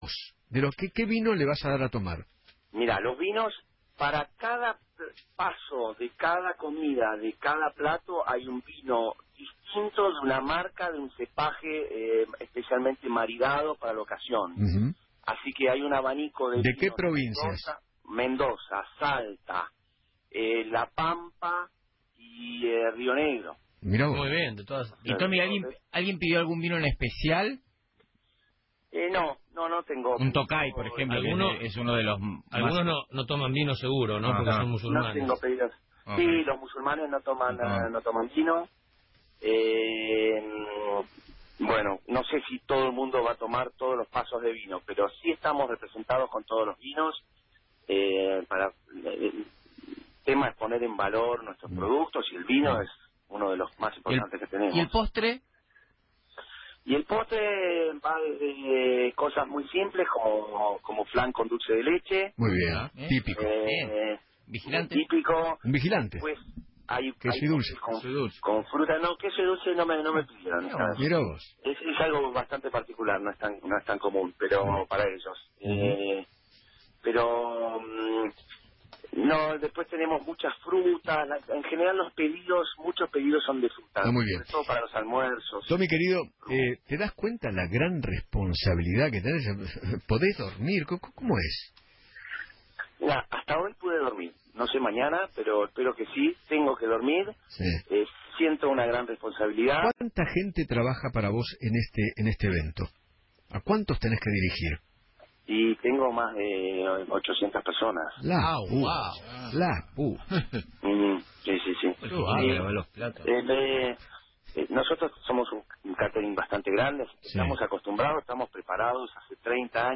habló en Feinmann 910 y contó que “Van a comer choripan